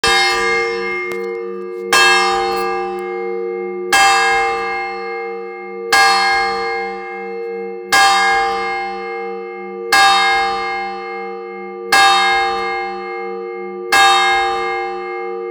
cloche (n°2) - Inventaire Général du Patrimoine Culturel